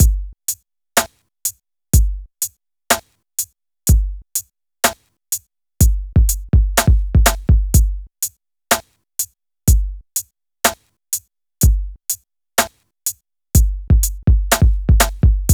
BEAT 1 62-R.wav